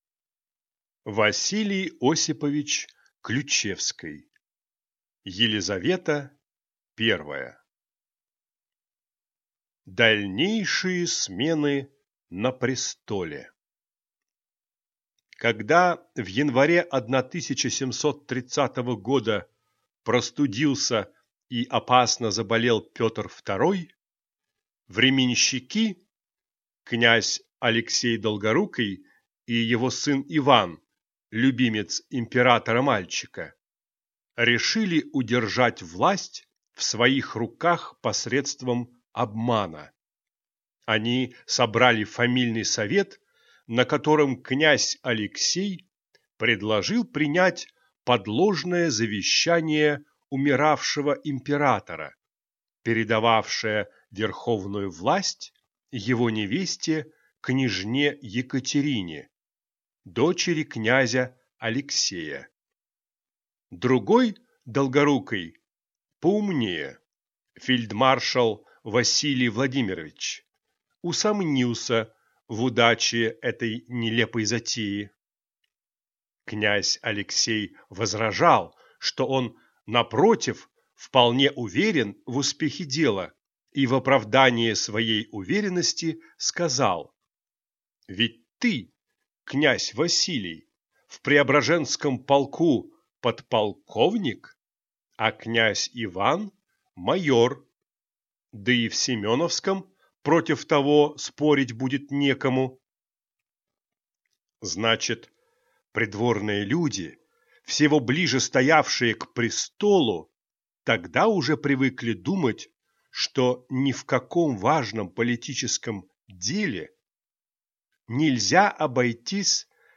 Аудиокнига Елизавета I | Библиотека аудиокниг